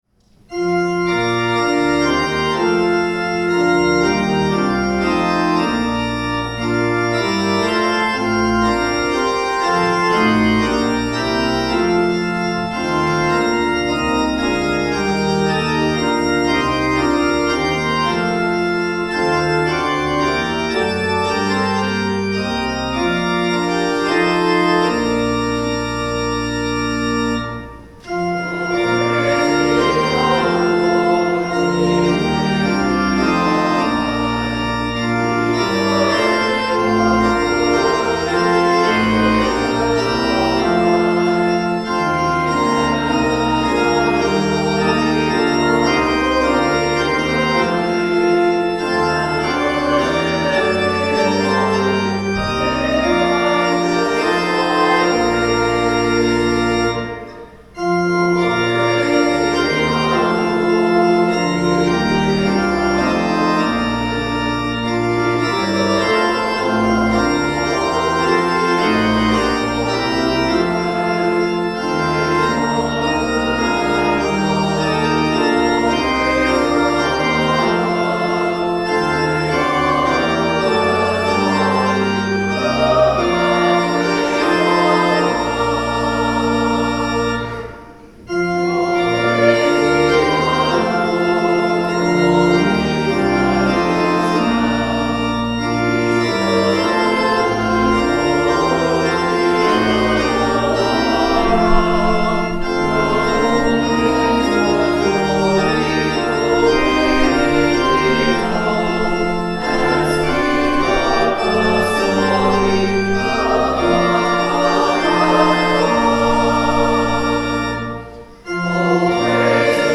Sermons | St John the Evangelist
The Lord’s Prayer (sung)